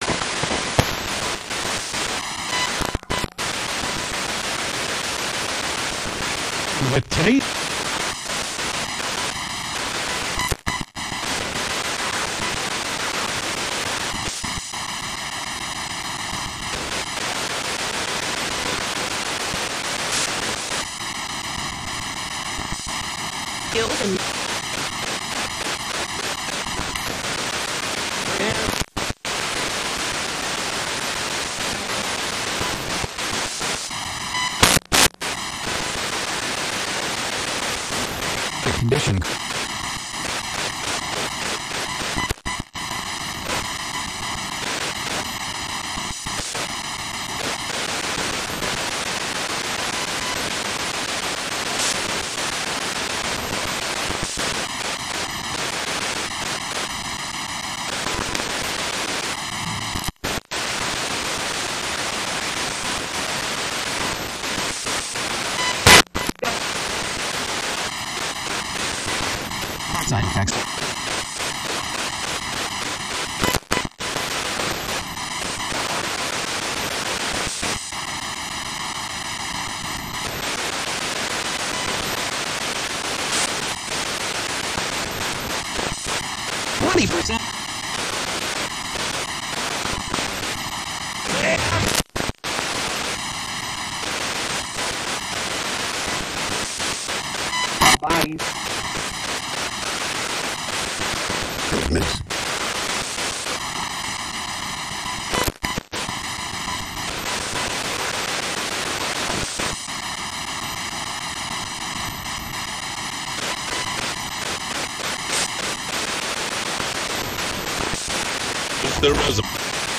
This is the documentation of a paranormal investigation of the Jennie Wade House in Gettysburg, PA.
sbox-jennie-wade-house-8.20.20-inside-museum.mp3